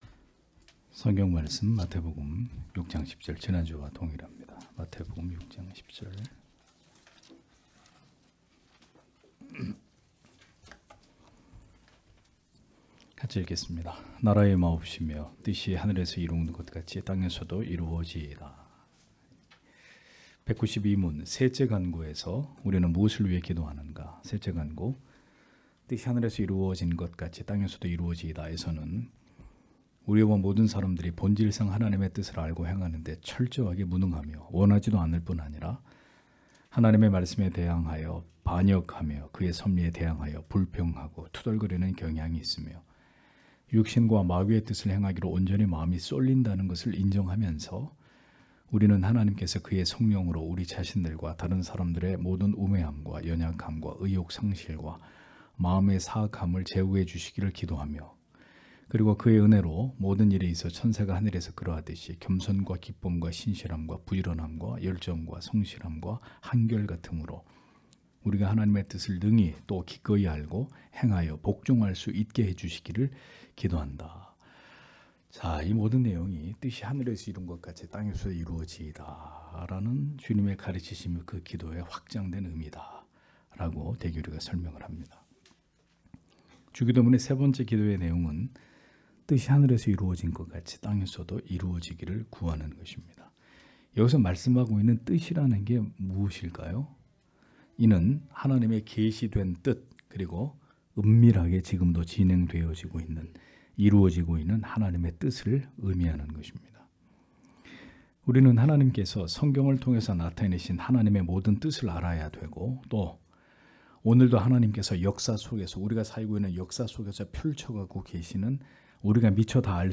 주일오후예배 - [웨스트민스터 대요리문답 해설 192] 192문) 셋째 기원에서 우리는 무엇을 기도하는가? (마태복음 6장 10절)